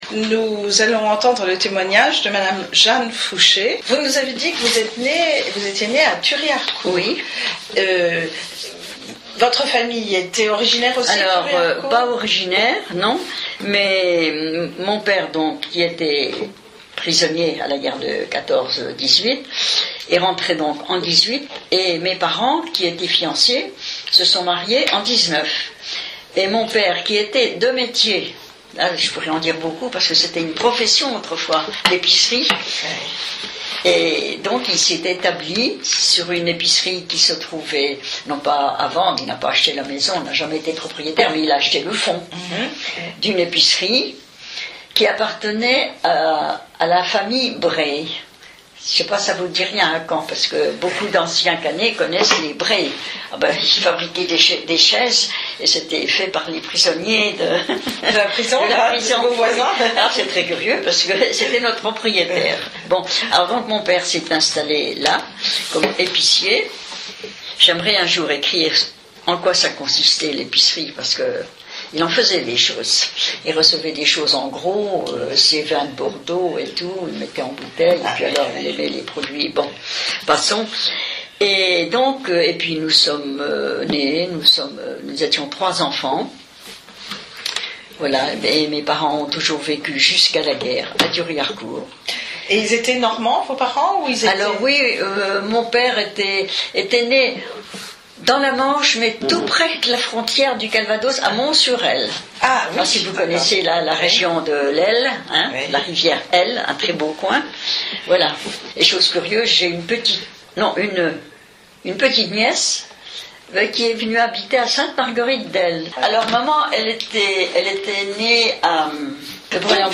Cette interview est issue du fonds d'enregistrements de témoignages oraux relatifs à la Seconde Guerre mondiale , collectés dans le cadre du projet de recherche Mémoires de Guerre de l'Université de Caen Basse-Normandie.